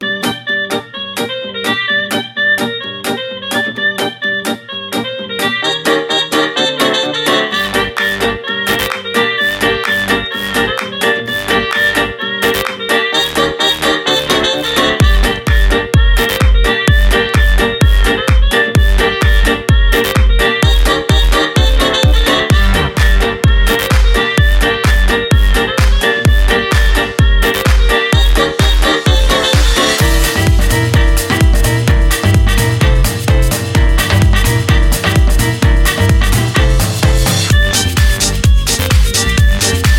танцевальные , без слов , электро-свинг , зажигательные